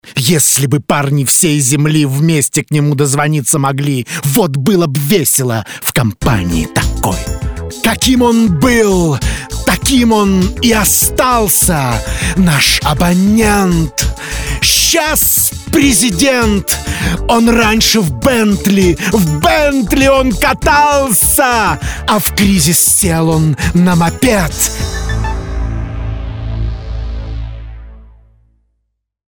Parodiya_na_Moiseeva.mp3